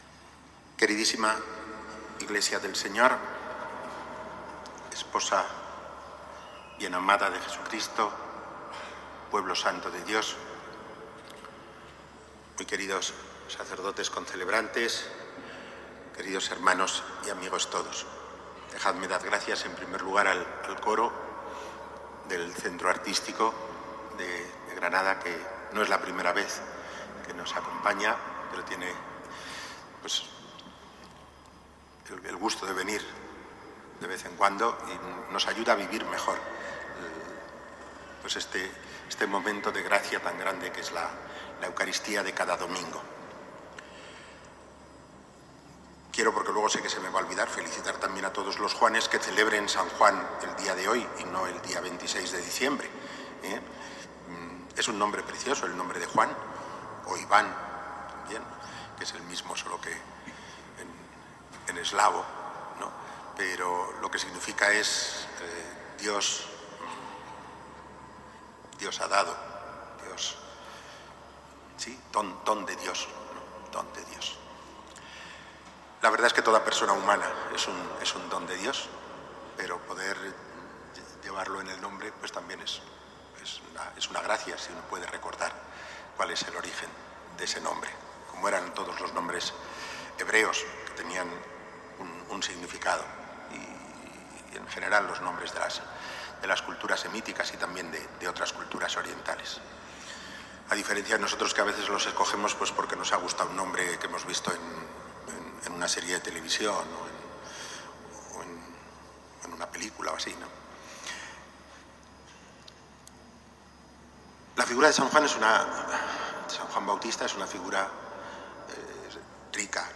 Homilía en la Eucaristía del jueves del Corpus Christi en la Catedral de Granada, concelebrada por el clero diocesano y asistencia de autoridades, representantes de cofradías y pueblo cristiano.